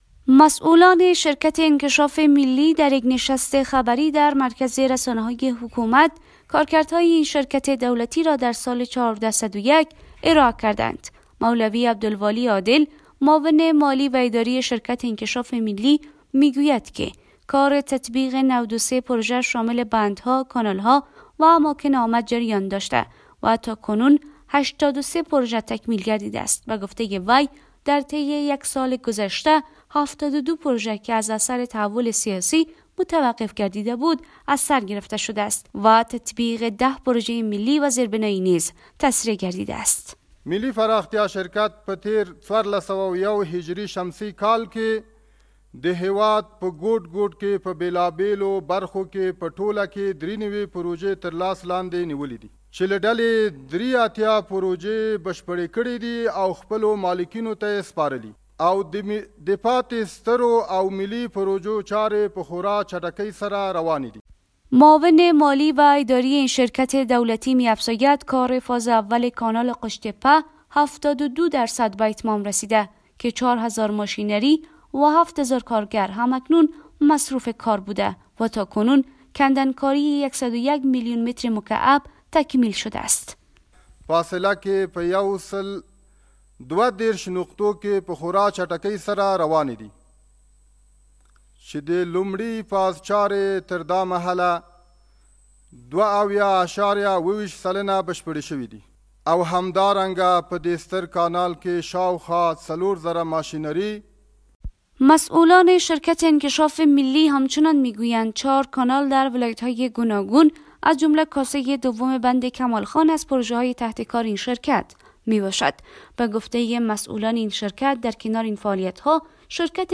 در کنفرانس مطبوعاتی که در مرکز اطلاعات و رسانه های حکومت طالبان برگزار گردید، مسئولان شرکت انکشاف ملی گزارش اجراات یک سال اخیر را ارائه کردند.